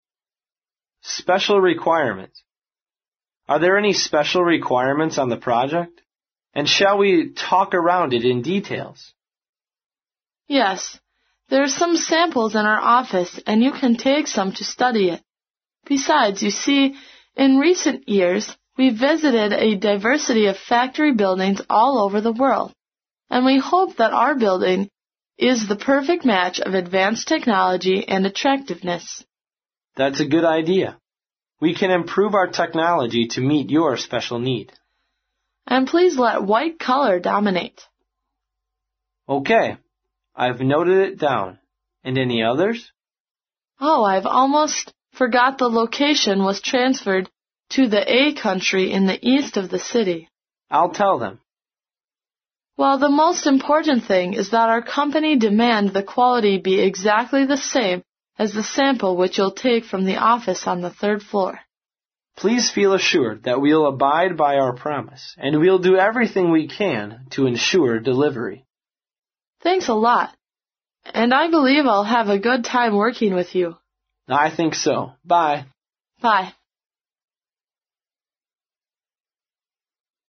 在线英语听力室外贸英语话题王 第61期:特殊投标的听力文件下载,《外贸英语话题王》通过经典的英语口语对话内容，学习外贸英语知识，积累外贸英语词汇，潜移默化中培养英语语感。